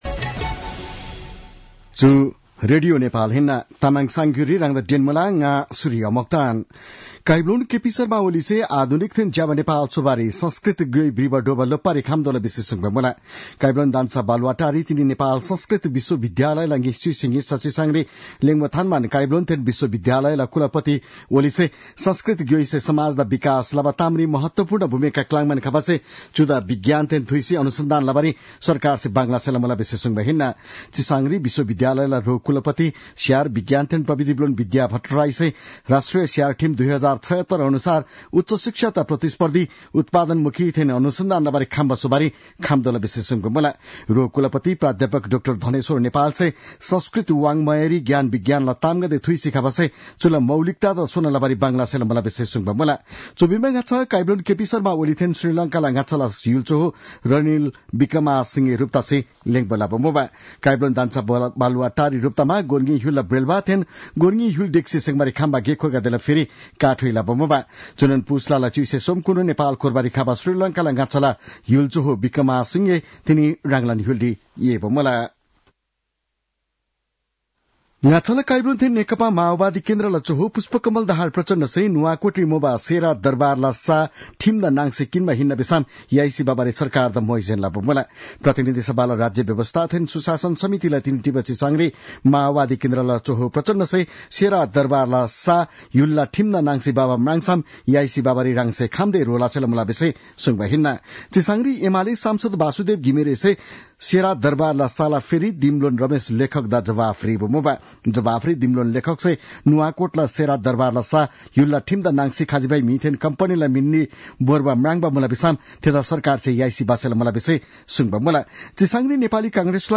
तामाङ भाषाको समाचार : १९ पुष , २०८१
Tamang-news-9-18.mp3